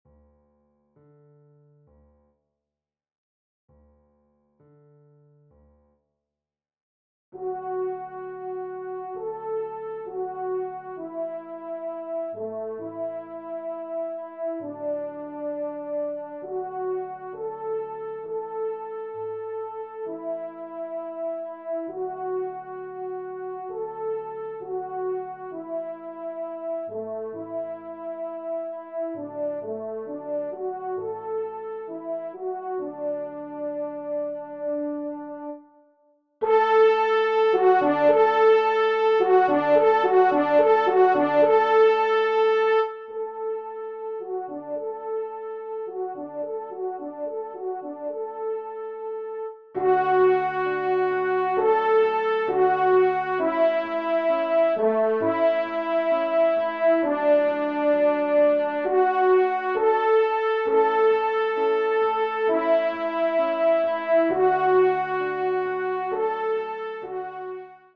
Genre :  Divertissement pour Trompe ou Cor et Piano
1e Trompe